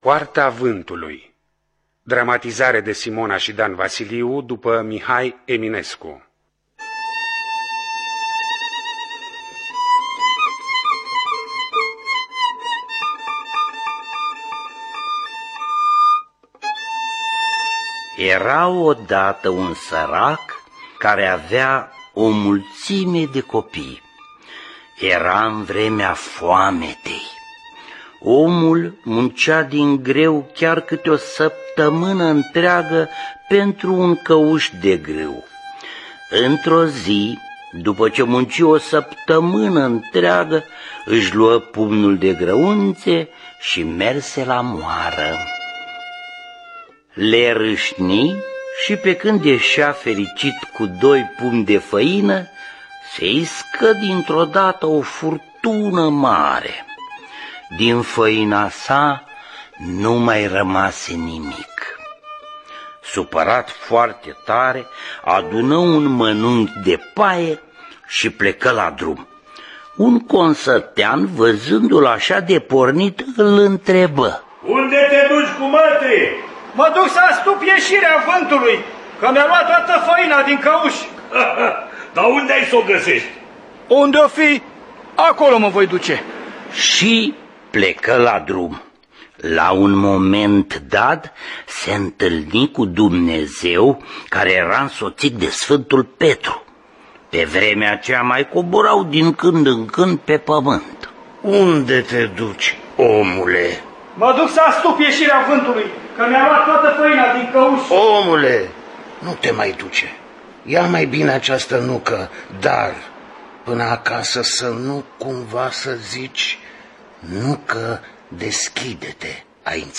Adaptarea radiofonică de Simona și Dan Vasiliu.